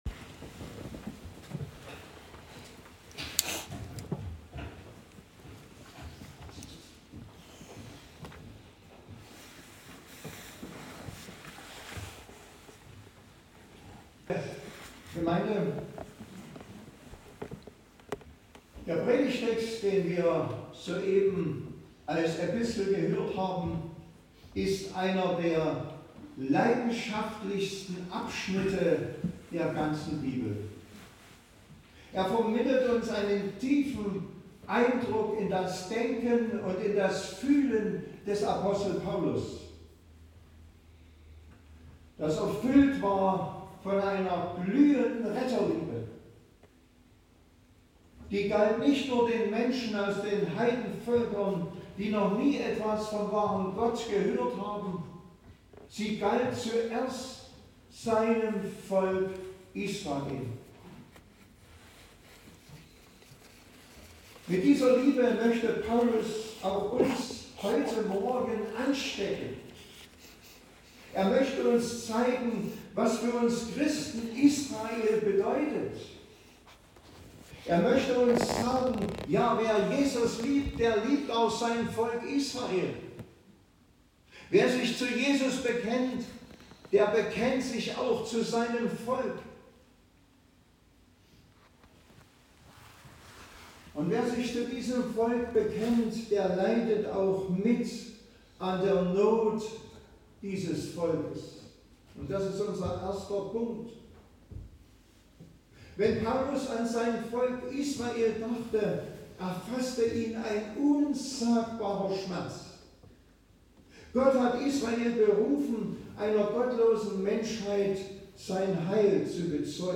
Passage: Römer 11;25-31 Gottesdienstart: Predigtgottesdienst Obercrinitz « Eine lebendige Gemeinde ist dort